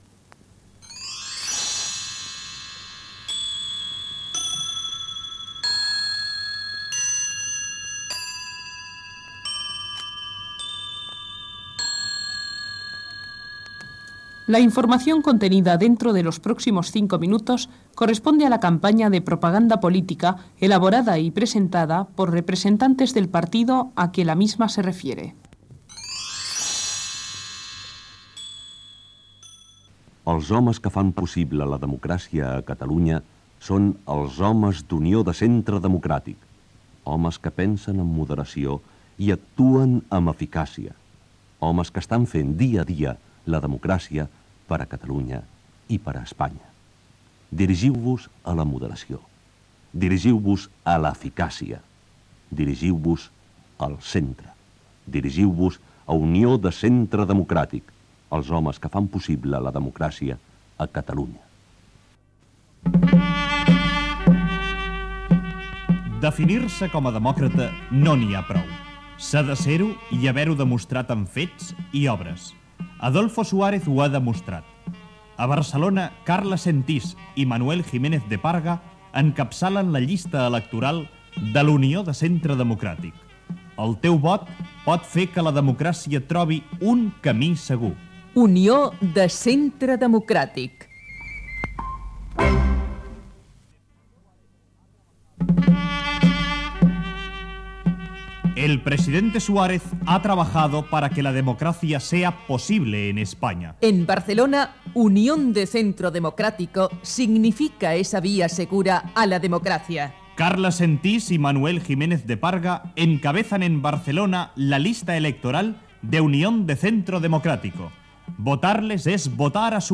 Campanya de Propaganda Política: Unión de Centro Democrático. Amb una entrevista al candidat Juan de Diós Ramírez Heredia